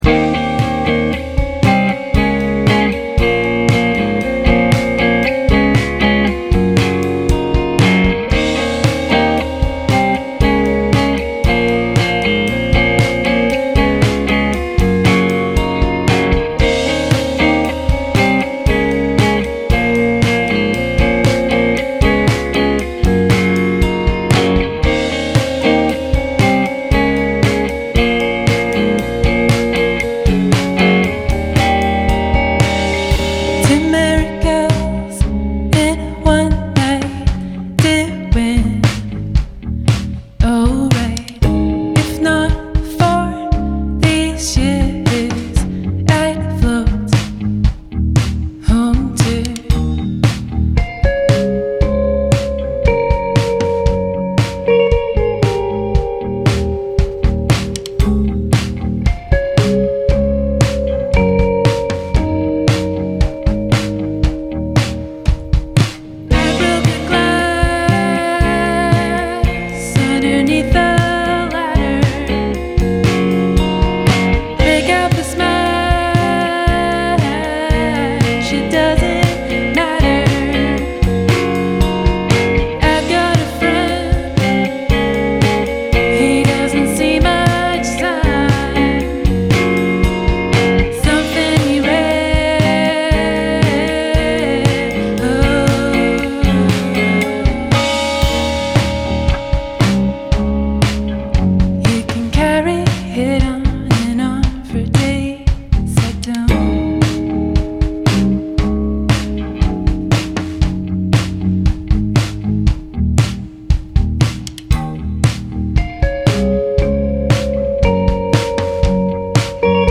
guitar pop